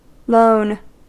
Ääntäminen
IPA: [sœl]